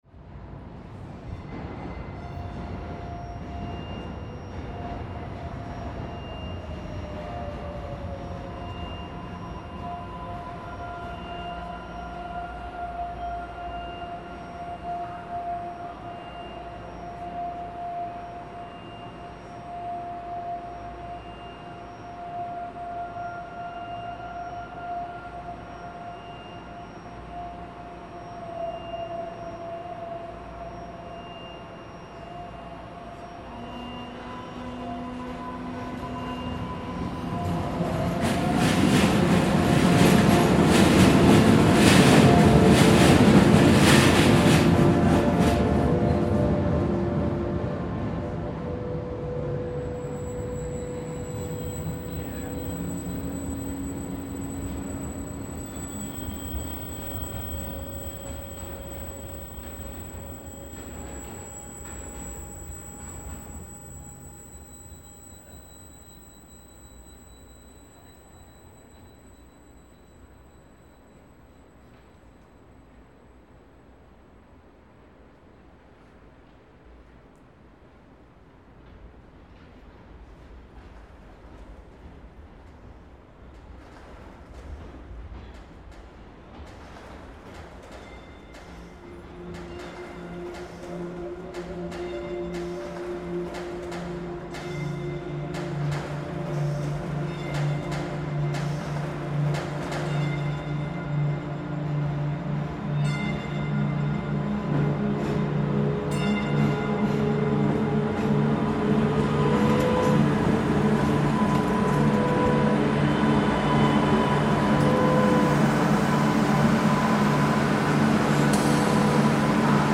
Boston T train reimagined